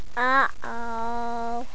uhoh.wav